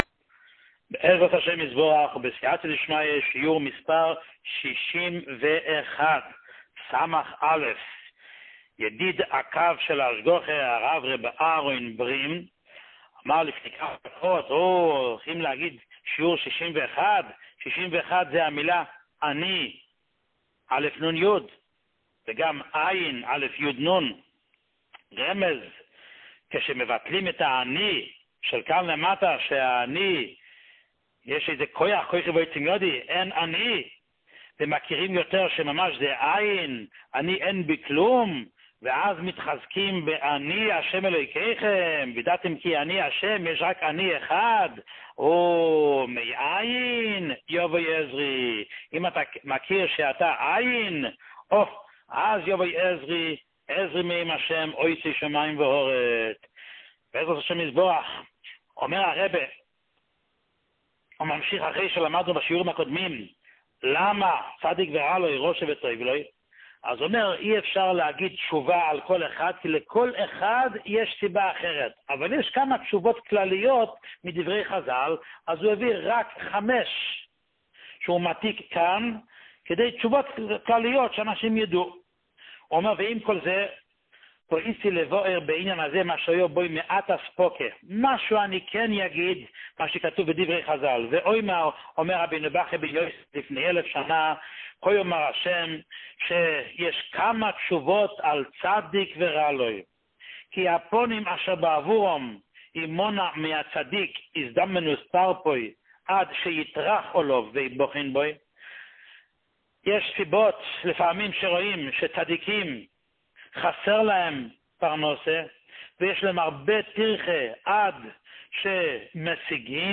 שיעורים מיוחדים